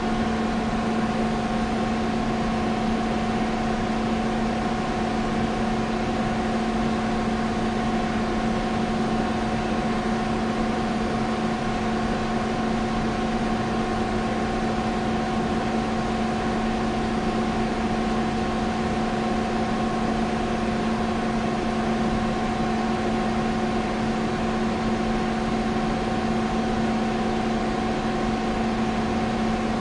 随机 "大房间里的嗡嗡声电力变压器2
描述：嗡嗡声电变压器在大room2.flac
Tag: 电动 变压器 嗡嗡声